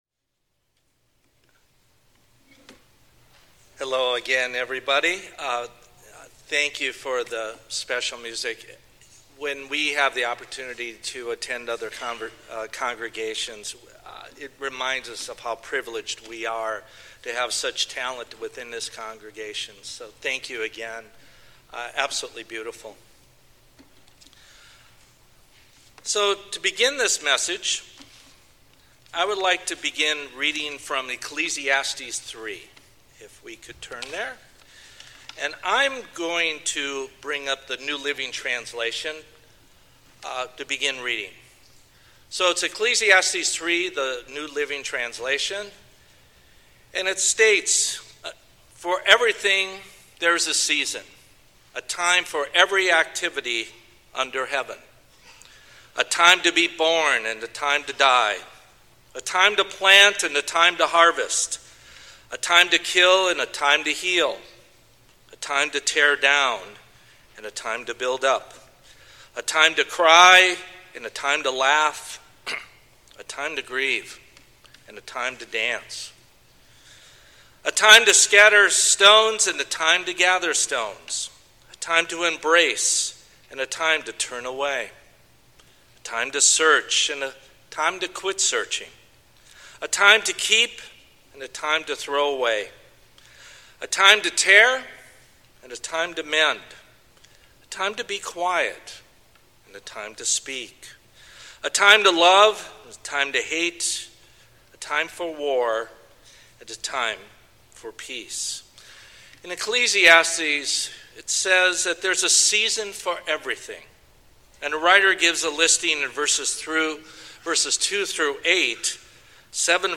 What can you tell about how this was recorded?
Given in Bakersfield, CA Los Angeles, CA